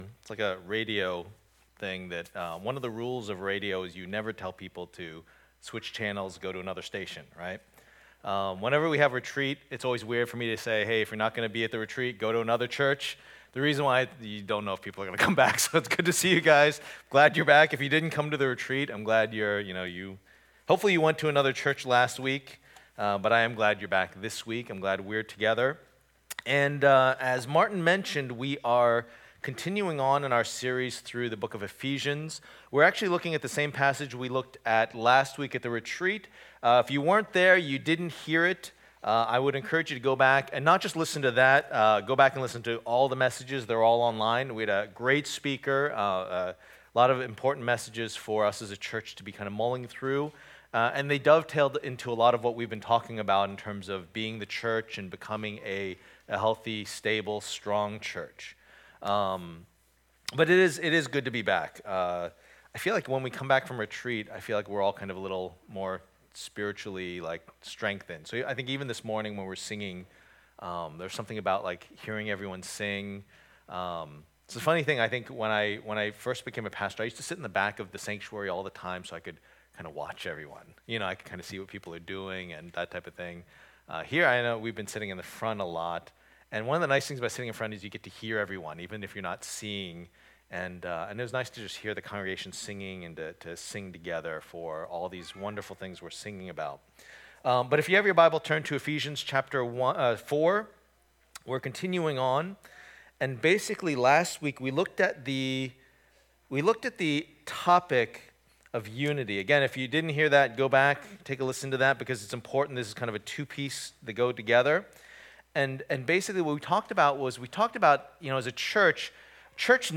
Passage: Ephesians 4:1-16 Service Type: Lord's Day